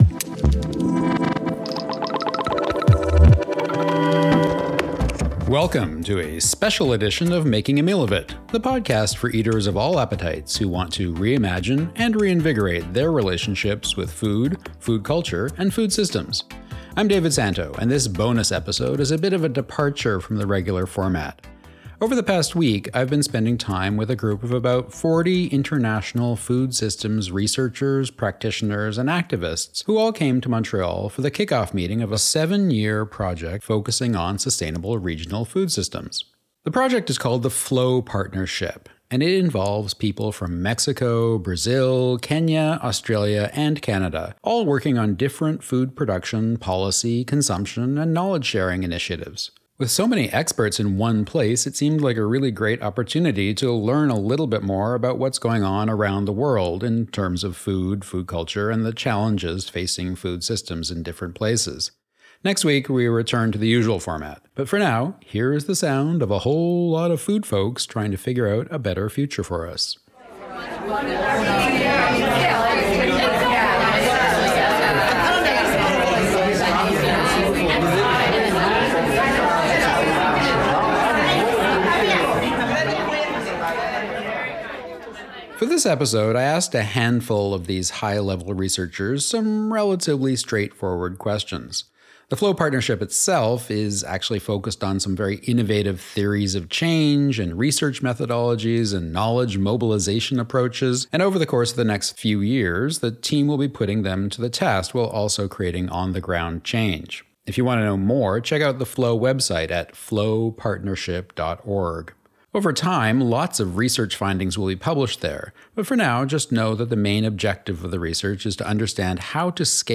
This special episode of Making a Meal of It features 11 short conversations with participants in the FLOW Partnership, a seven-year international food systems research project. The full team met in Montreal in mid-May 2024 to share their progress, plan future research activities, and discuss ways to enable their work to transform food production, policies, and attitudes.